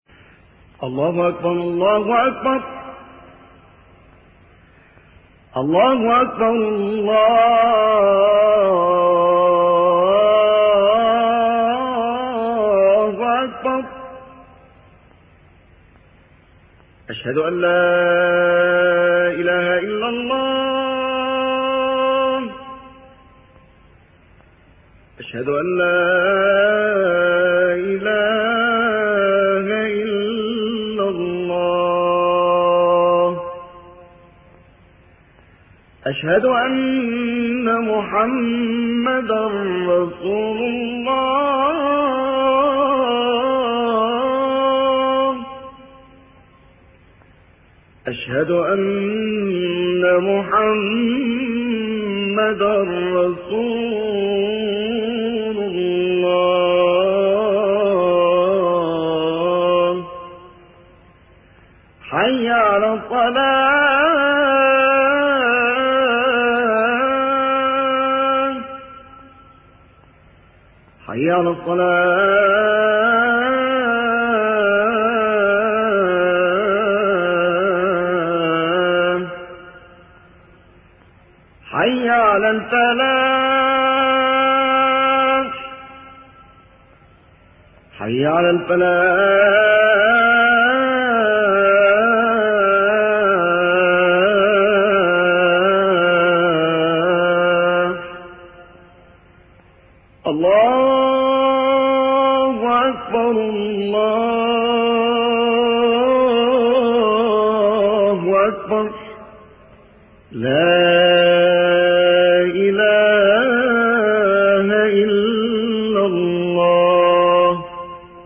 أناشيد ونغمات
عنوان المادة أذان-5